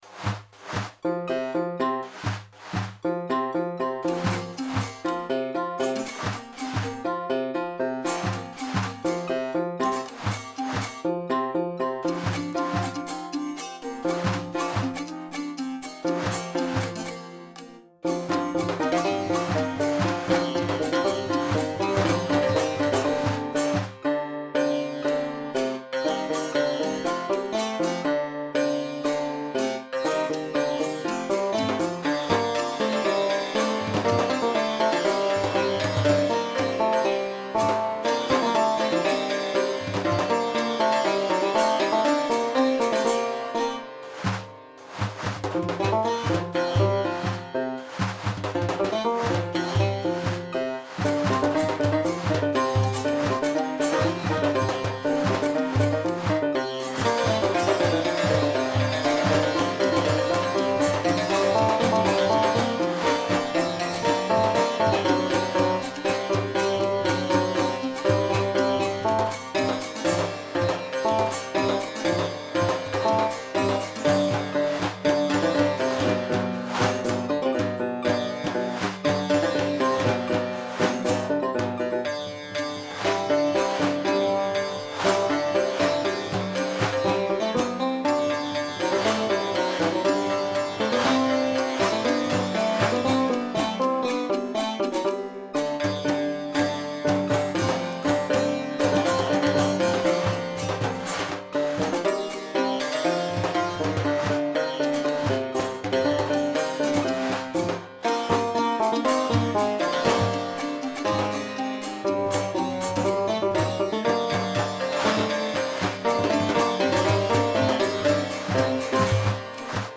Dabei handelt es sich um 10 Instrumente aus dem heutigen Iran, die in der Library zusammengefasst wurden.